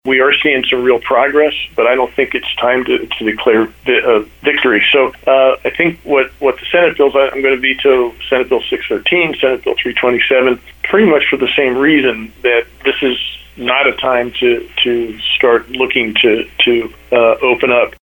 Pennsylvania Governor Tom Wolf talks about what he will do with legislation passed by the state House and Senate which would reopen many businesses in PA which were closed in response to the COVID 19 pandemic.